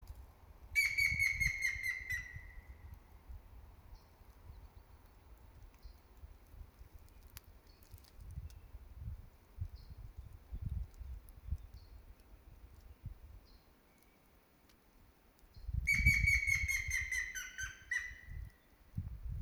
Pelēkā dzilna, Picus canus